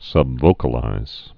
(sŭb-vōkə-līz)